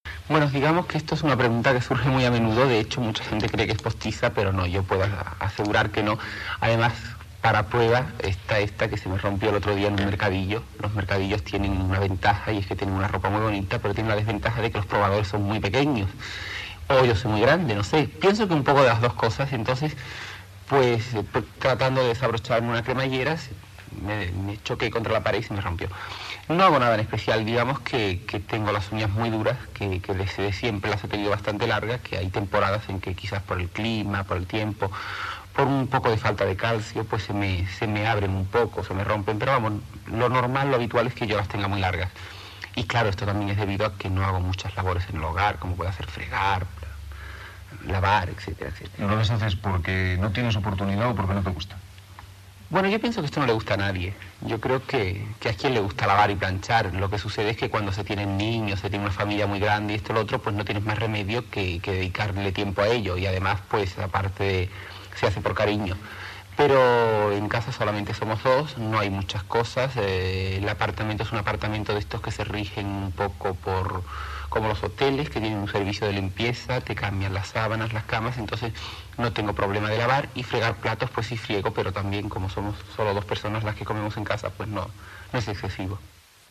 Entrevista a l'actriu Bibi Andersen (Bibiana Manuela Fernández) qui parla de les seves ungles llargues
Entreteniment